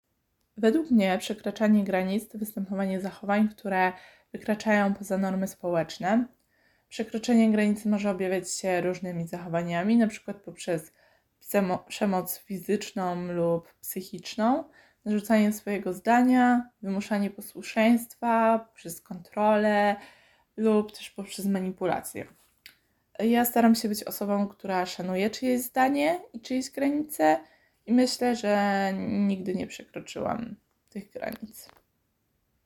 Zapytaliśmy studentów, co według nich oznacza “przekraczanie granic” i czy mieli okazję podjąć się tego w życiu: